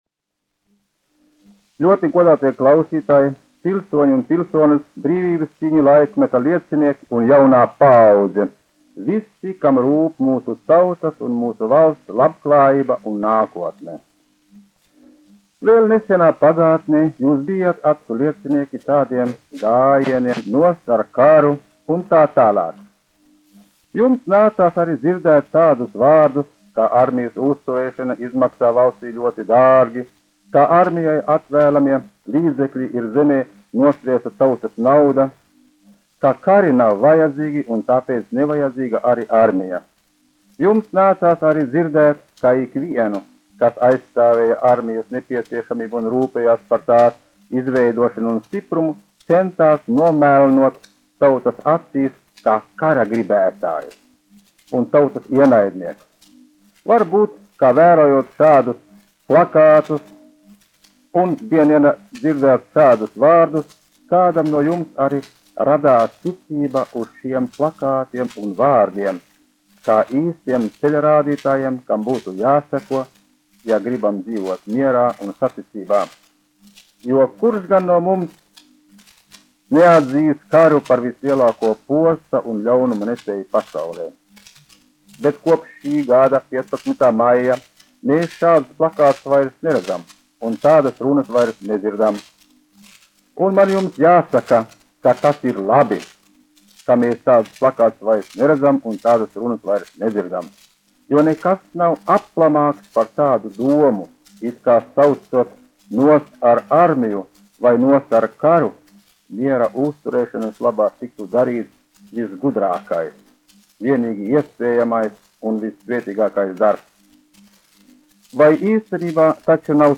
Ģenerāļa Hartmaņa runa : 17.11.1934
1 skpl. : analogs, 78 apgr/min, mono ; 25 cm
Astoņpadsmitā novembra svētku runas
Svētku runas--Latvija
Skaņuplate